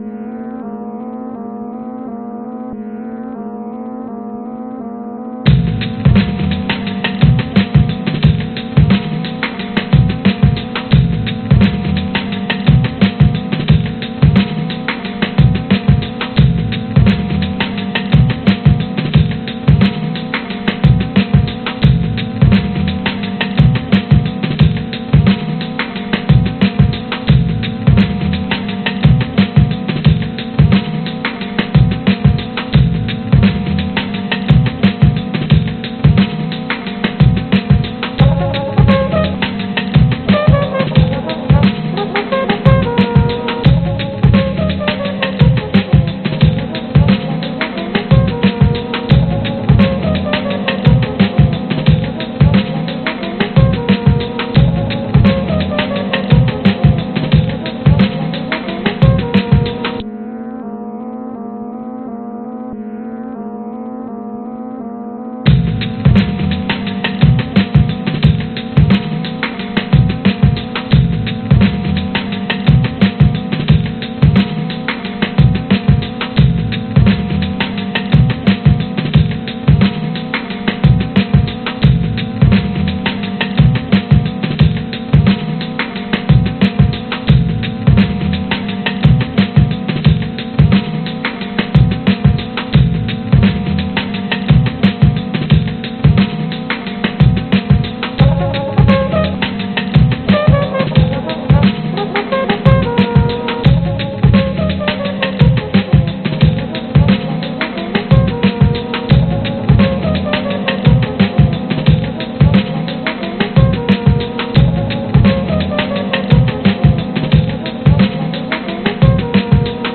描述：灵感来自于老式的Hip Hop，实验性地使用了样本